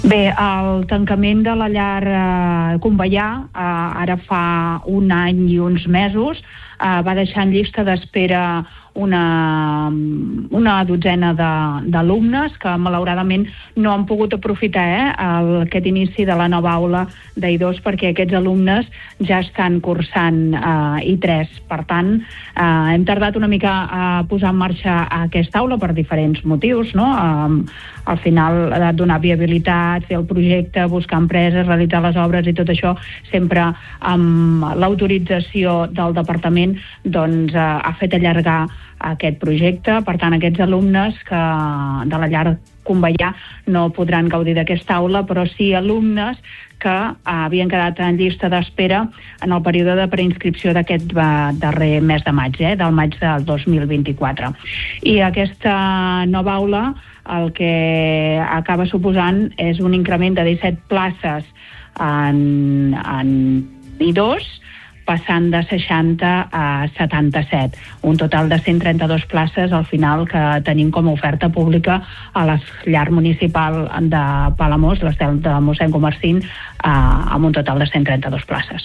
Entrevistes Supermatí
I per parlar d’aquesta ampliació ens ha visitat al Supermatí la regidora d’educació de l’Ajuntament de Palamós, Yolanda Aguilar.